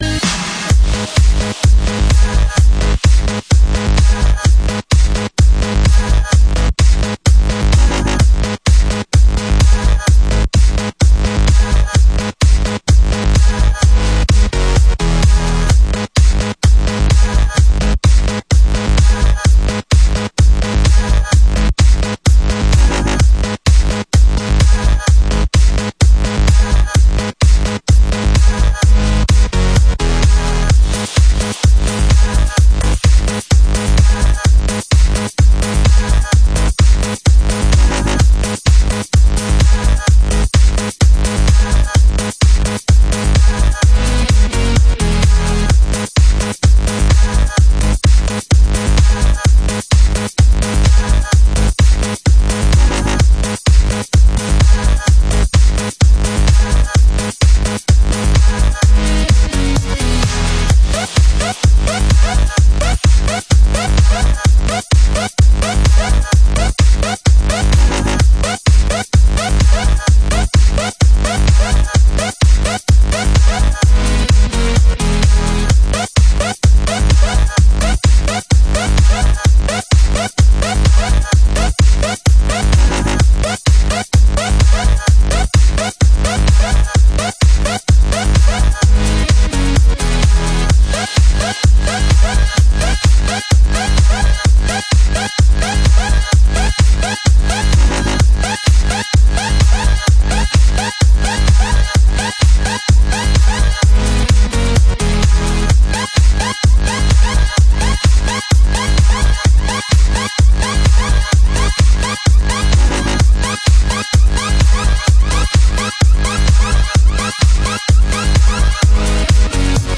Category: Neotrance